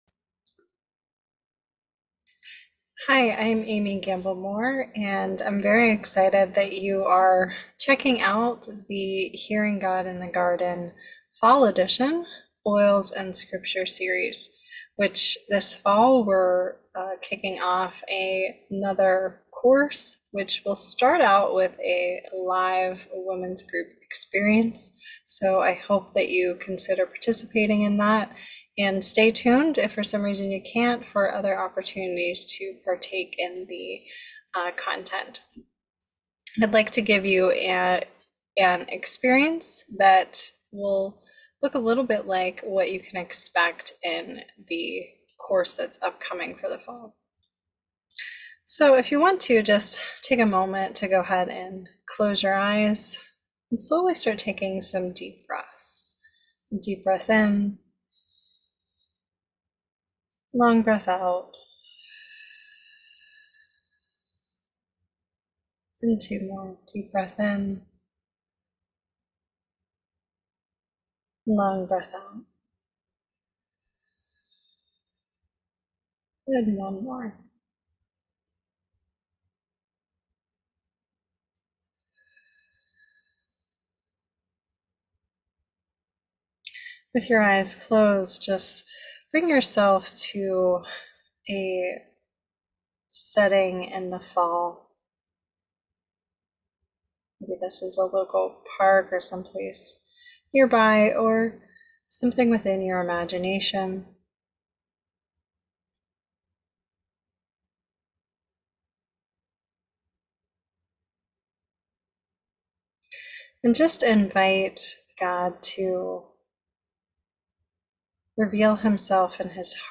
Ground through this guided meditation with the natural experiences in fall as you Hear God in the Garden.